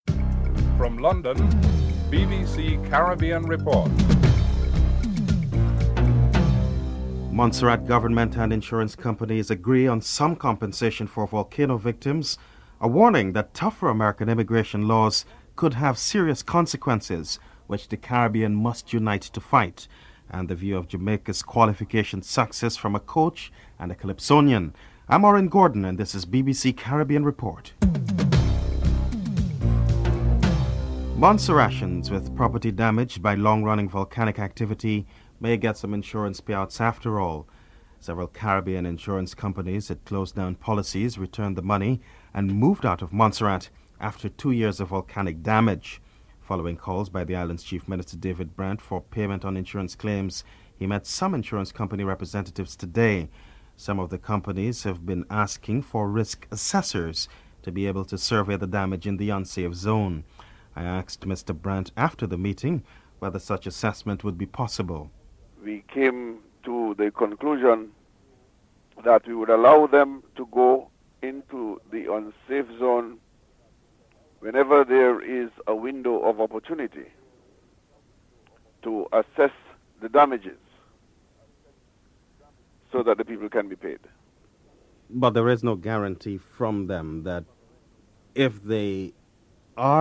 1. Headlines (00:00-00:30)
2. Montserrat government and insurance companies agree on some compensation for volcano victims. Chief Minister David Brandt is interviewed (00:31-03:58)